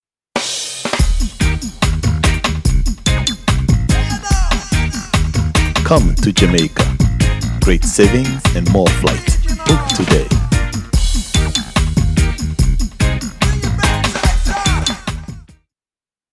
Caribbean Jamaican V/O VO Voiceover